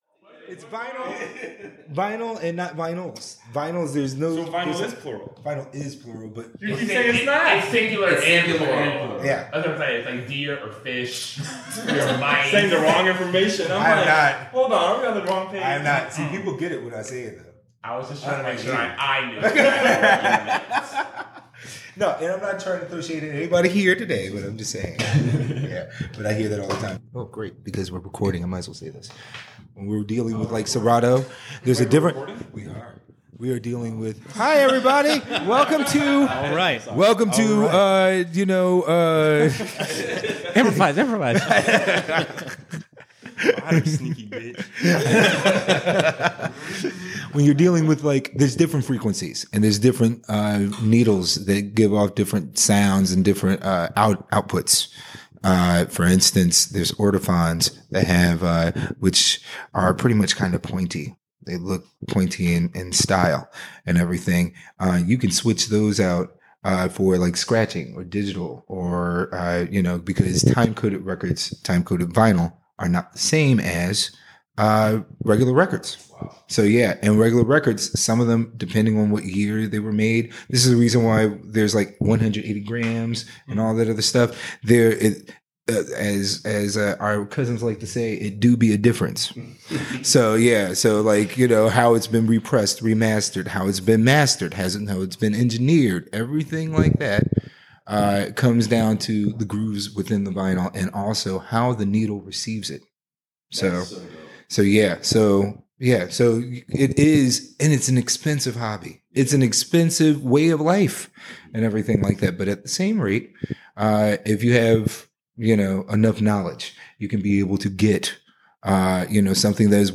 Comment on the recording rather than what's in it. This episode was recorded during "Gay Icons" night